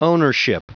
Prononciation du mot ownership en anglais (fichier audio)
Prononciation du mot : ownership